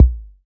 Kick Beatboxer R3.wav